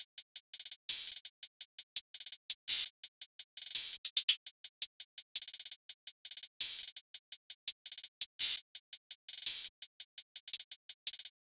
Cardiak_HiHat_Loop_4_168bpm.wav